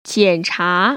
[jiănchá] 지엔차  ▶